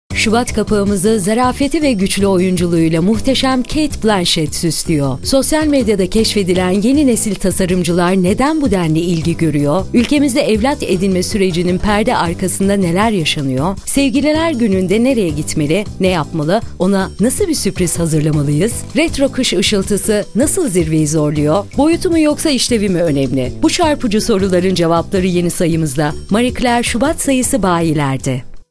Versatile and expressive voice-over artist with a background in radio, music, and storytelling.
Clear diction, emotional range, and a warm, engaging tone – available in both English and Turkish.
Sprechprobe: Werbung (Muttersprache):